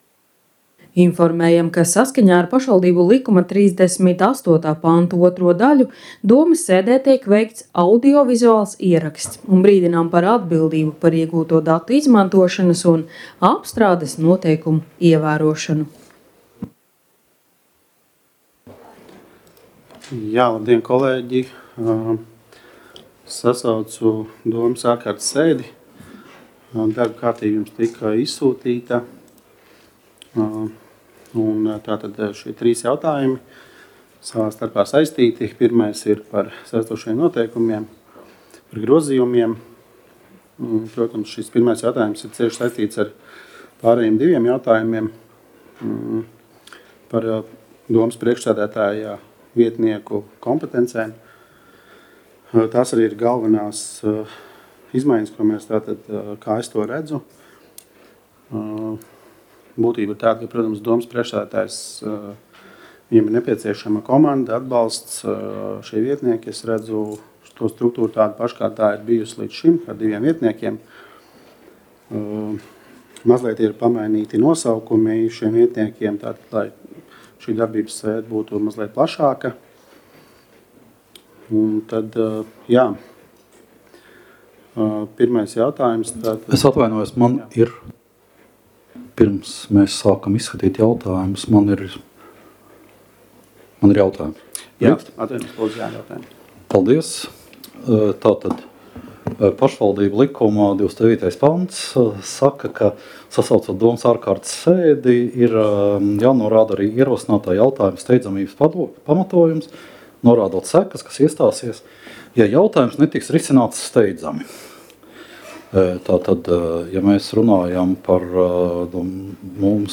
Talsu novada domes ārkārtas sēde Nr. 6
Domes sēdes audio